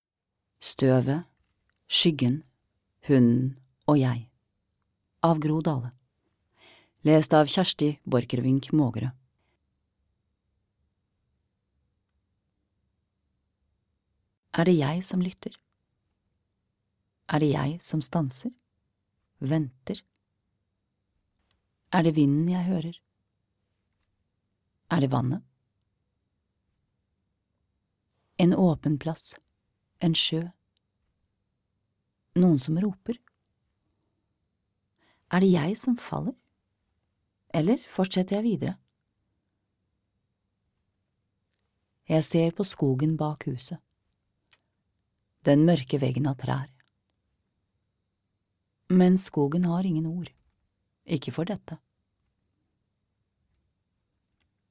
Støvet, skyggen, hunden og jeg (lydbok) av Gro Dahle